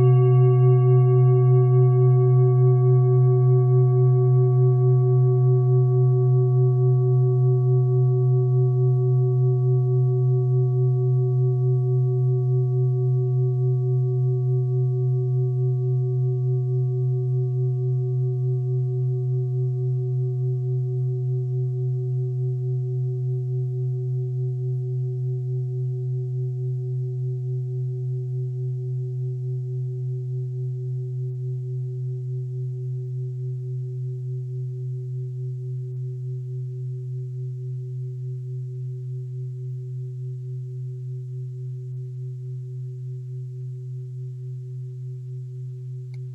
Klangschalen-Typ: Bengalen und Tibet
Klangschale 4 im Set 4
Durchmesser = 23,7cm
(Aufgenommen mit dem Filzklöppel/Gummischlegel)
klangschale-set-4-4.wav